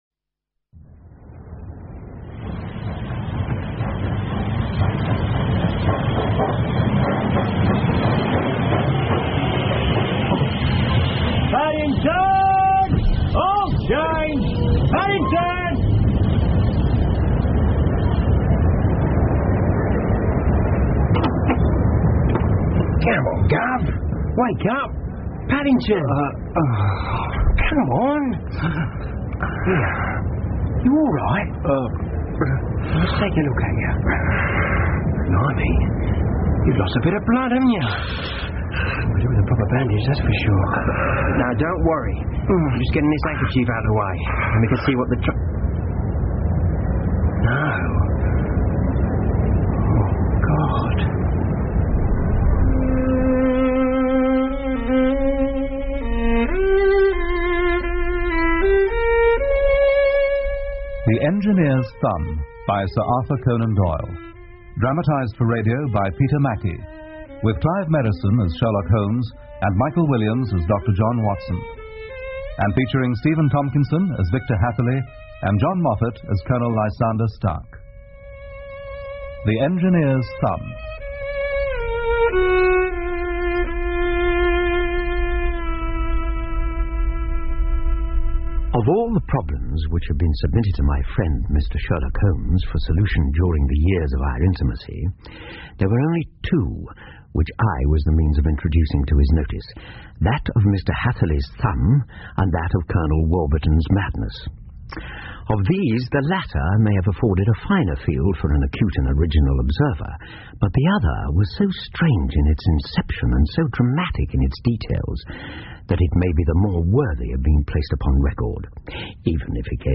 福尔摩斯广播剧 The Engineer's Thumb 1 听力文件下载—在线英语听力室